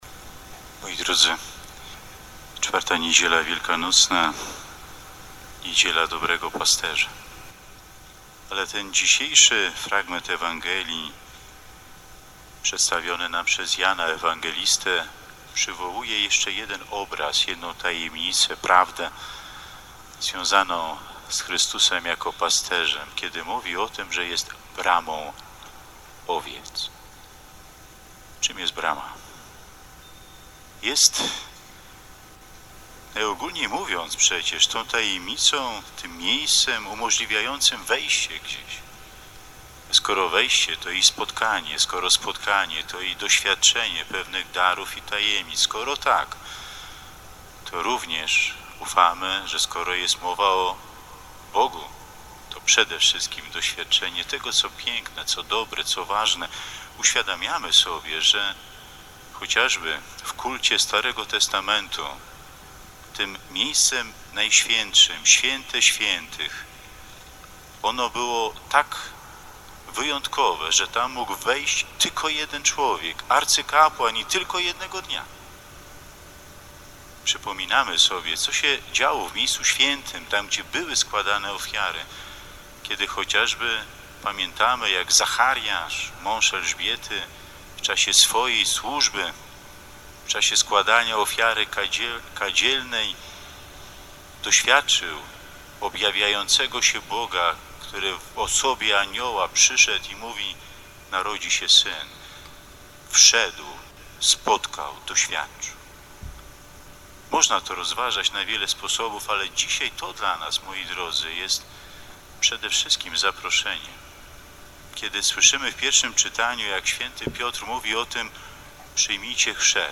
Tego dnia, biskup Marek Solarczyk odprawił poranną mszę świętą w Parafii Najczystszego Serca Maryi w Warszawie. W homilii duchowny podkreślił, że to Jezus otwiera nam drogę do Boga i On jest początkiem każdego powołania.
Poniżej cała homilia biskupa pomocniczego diecezji warszawsko-praskiej Marka Solarczyka z 3 maja 2020r.